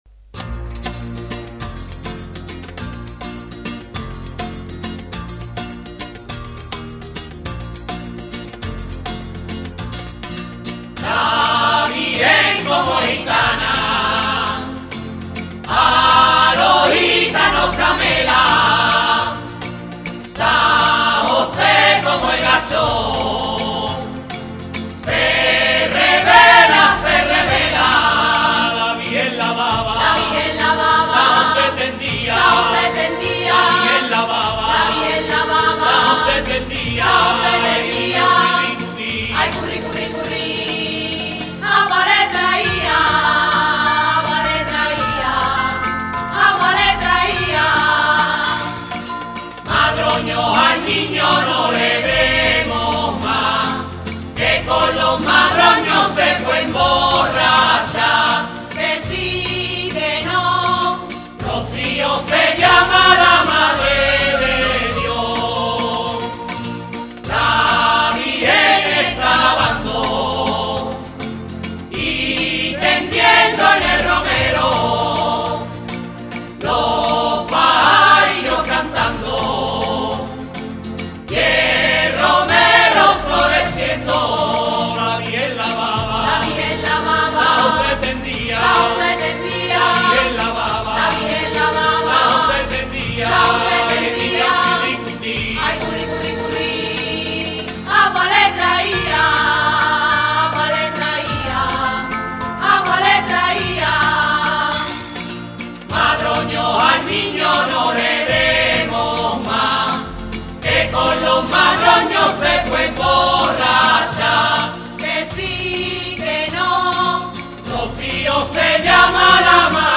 Coro de la Hermandad del Roció de Pilas